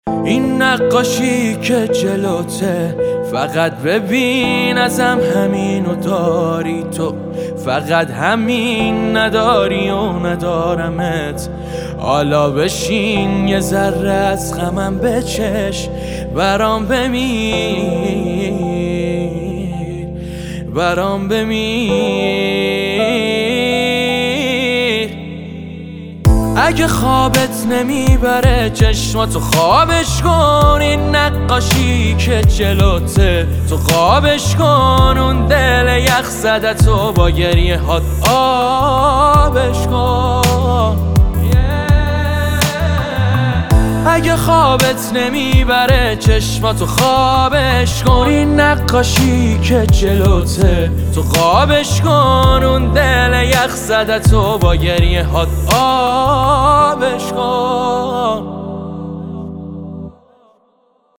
آهنگ دلی
غمگین